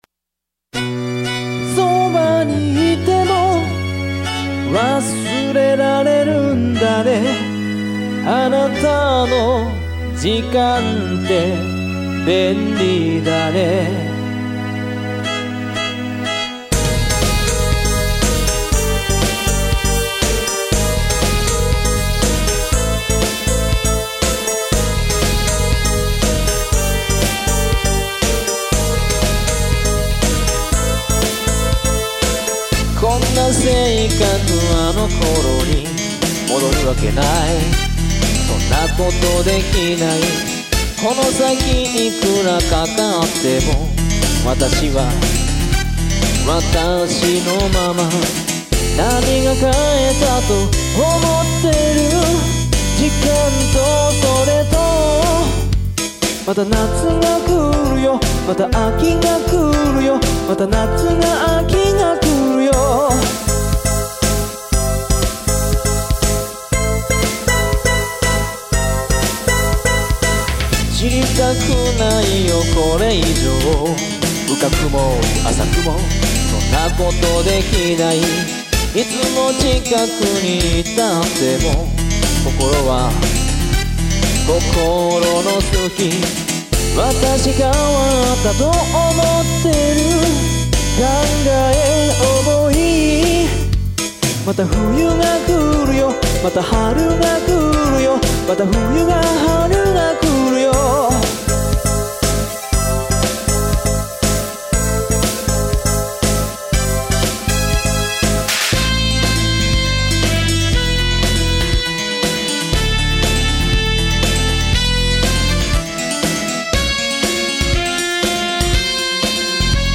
スタジオ録音／音楽収録用マイクロフォン使用